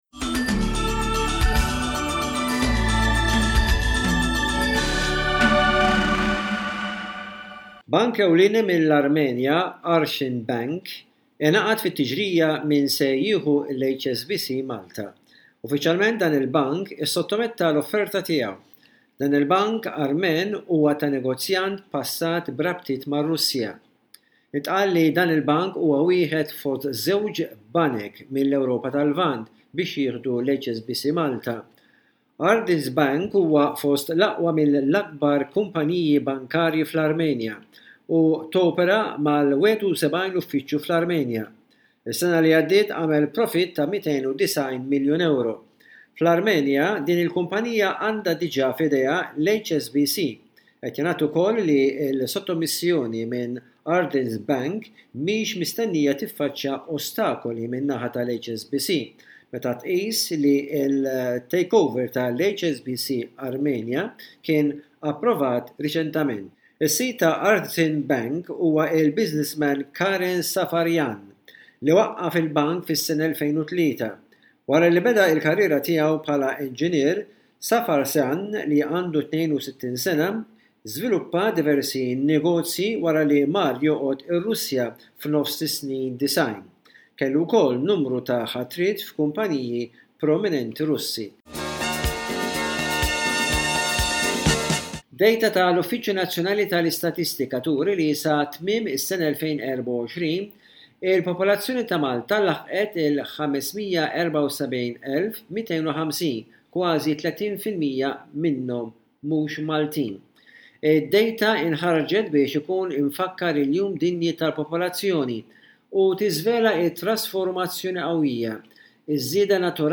Bullettin ta' aħbarijiet minn Malta mill-korrispondent tal-SBS